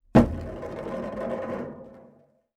Metal_04.wav